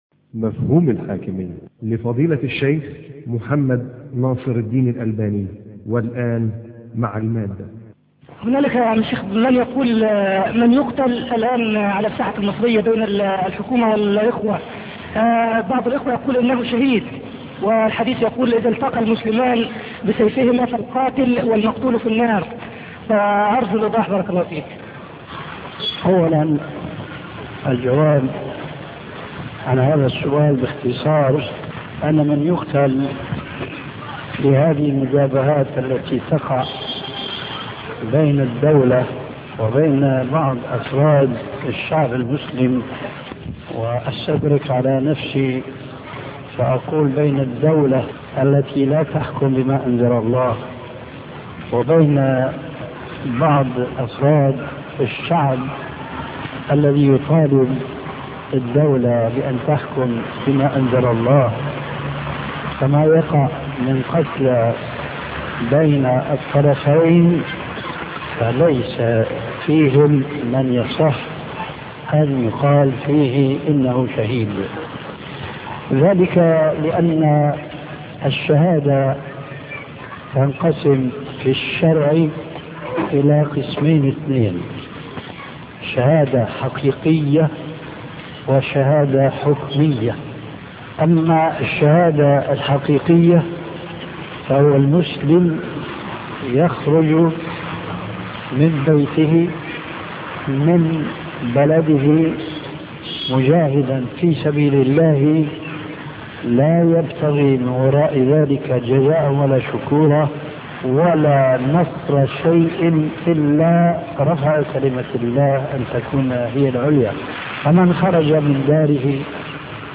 محاضرة مفهوم الحاكمية الشيخ محمد ناصر الدين الألباني